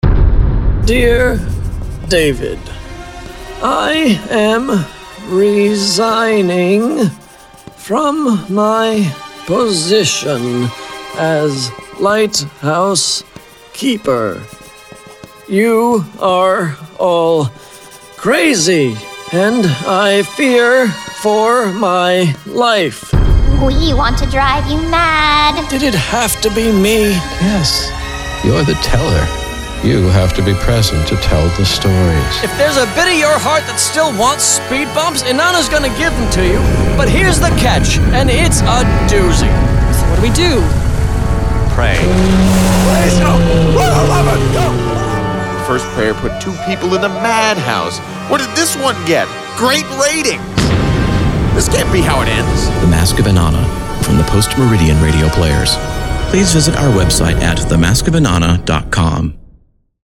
A bi-monthly nine-part miniseries, featuring a cast of over thirty performers.